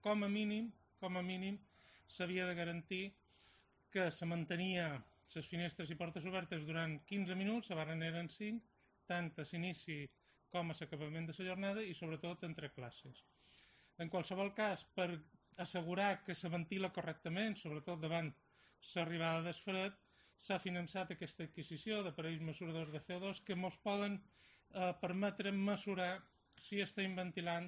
El director general de Planificación, Ordenación y Centros, Antoni Morante.